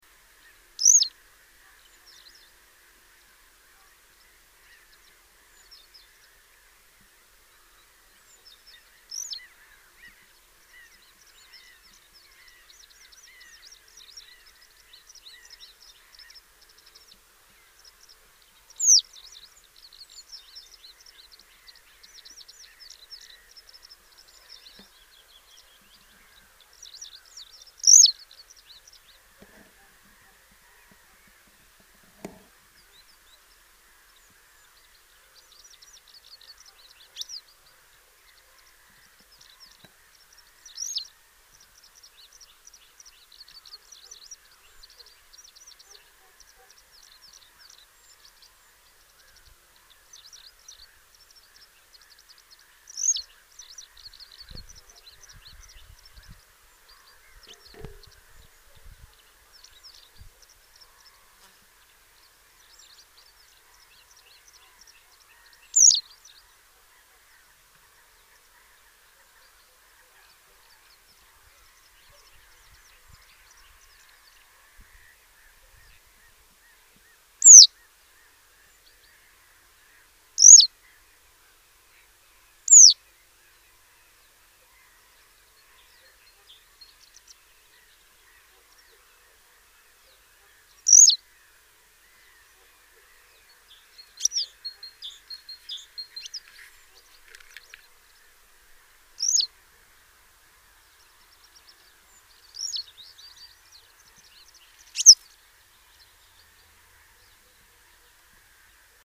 Cutrettola
(Motacilla flava)
Cutrettola-Motacilla-flava-thunbergi_flava.mp3